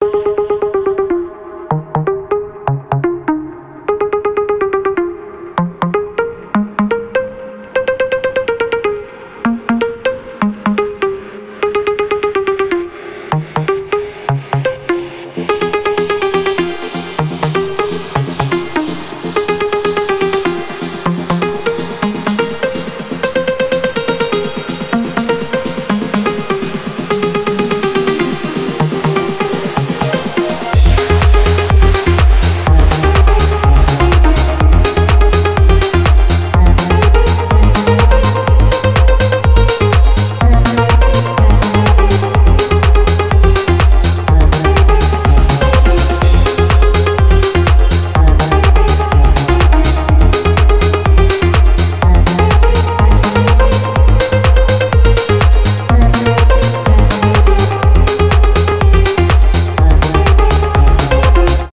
клубные , progressive house , без слов
нарастающие , electronic